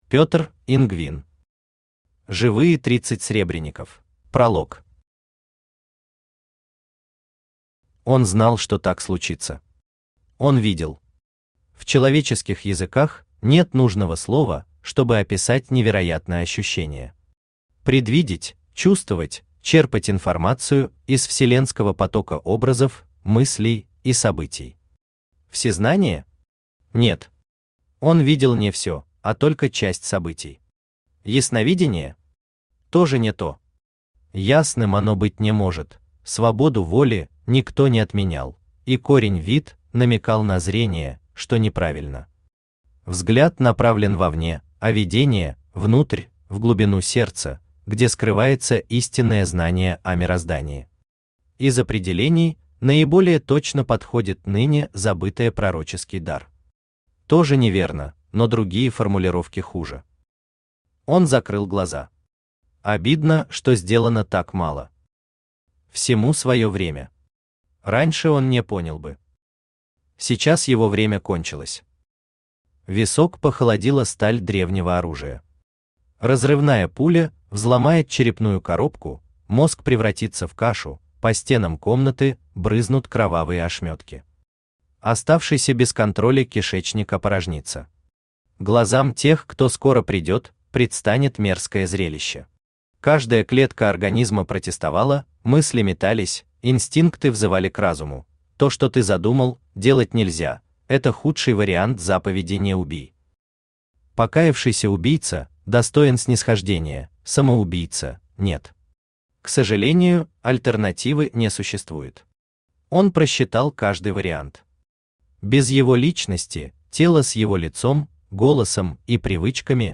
Аудиокнига Живые тридцать сребреников | Библиотека аудиокниг
Aудиокнига Живые тридцать сребреников Автор Петр Ингвин Читает аудиокнигу Авточтец ЛитРес.